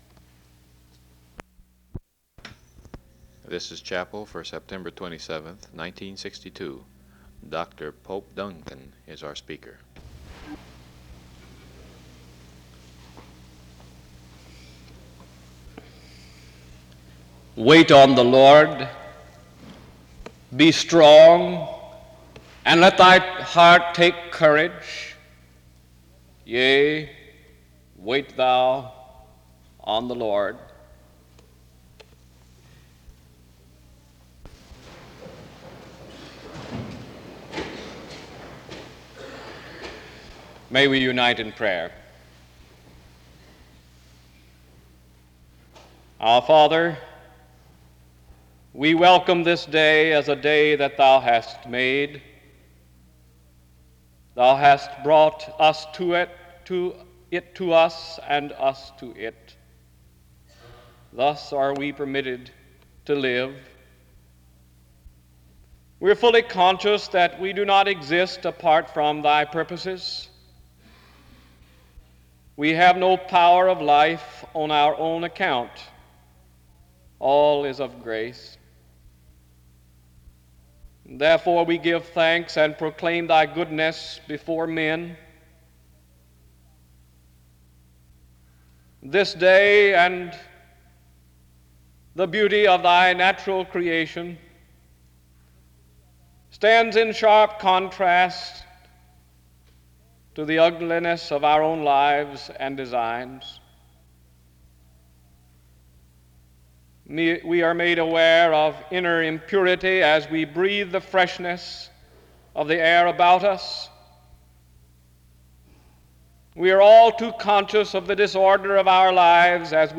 The service opens up with a prayer from 0:17-4:22. Next, there is a song from 4:42-8:18.
SEBTS Chapel and Special Event Recordings SEBTS Chapel and Special Event Recordings